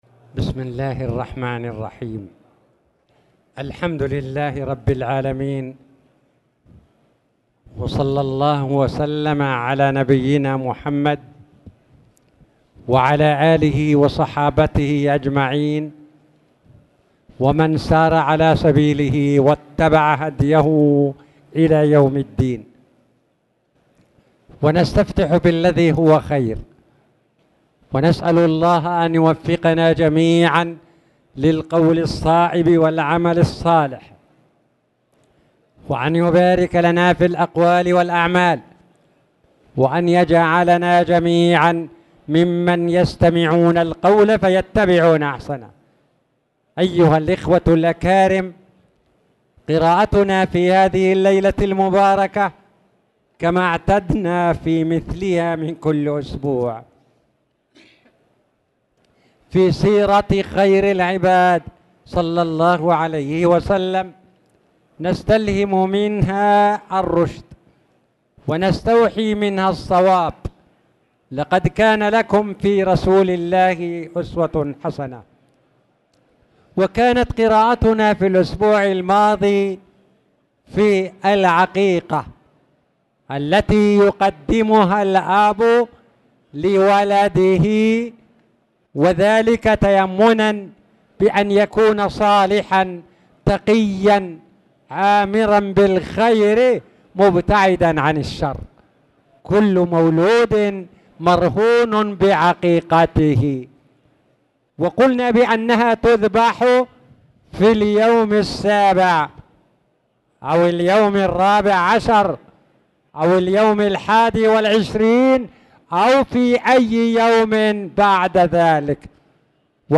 تاريخ النشر ٢١ ربيع الثاني ١٤٣٨ هـ المكان: المسجد الحرام الشيخ